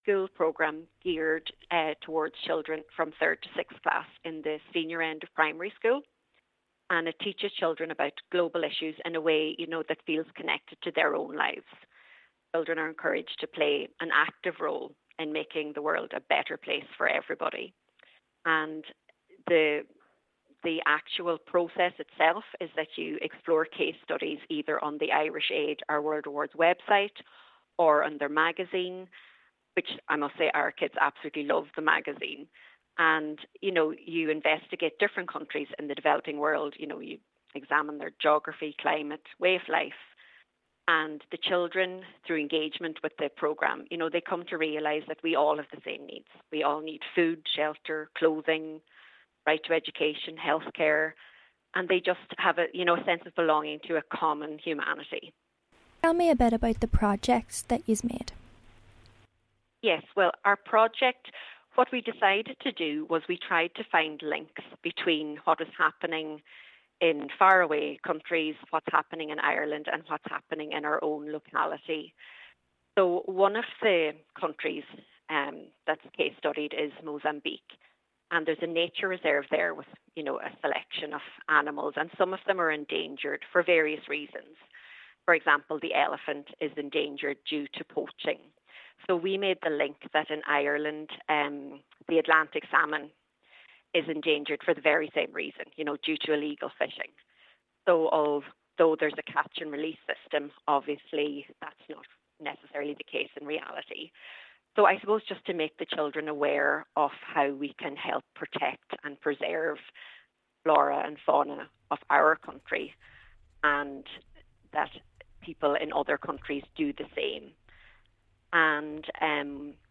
News, Audio, Top Stories